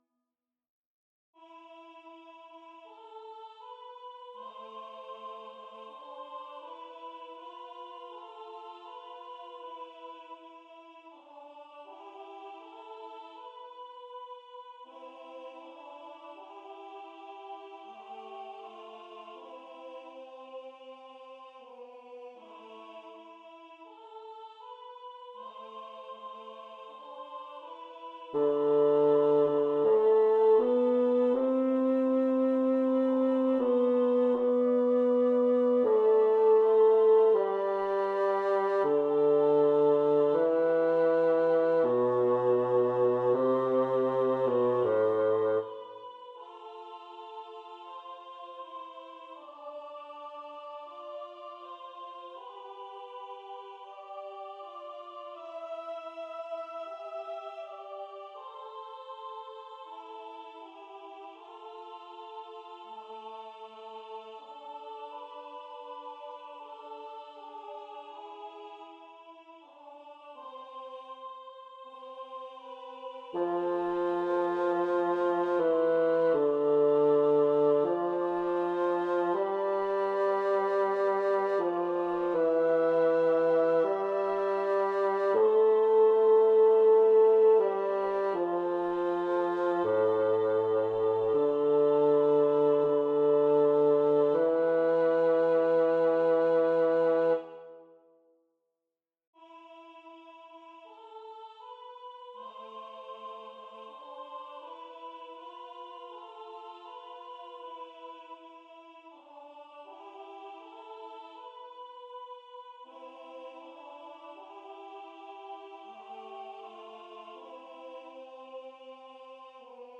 Esta semana os traigo otra obra de repertorio sacro correspondiente al periodo de Semana Santa: Pange Lingua, en la versión de Zoltán Kodály (1882-1967), para tres voces mixtas (soprano, alto y barítono).
El tempo aparece indicado como Andante: debe ser lo suficientemente ágil para que se aprecie el ritmo de cada entrada con sus respectivas imitaciones.
Para aprender la melodía os dejo estos MIDIs donde se escuchan las otras dos voces de fondo.
pange-lingua-tenor-y-bajo.mp3